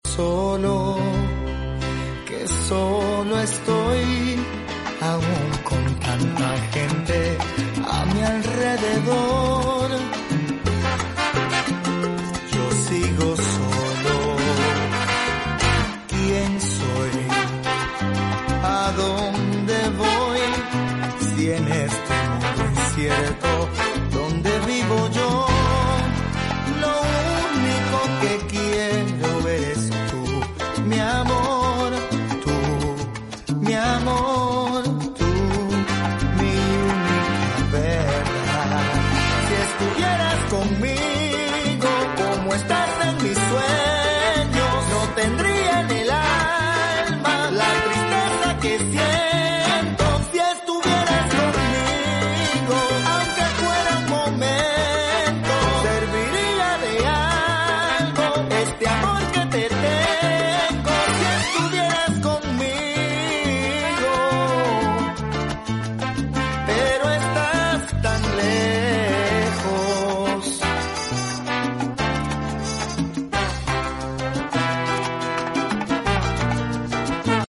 salsa romántica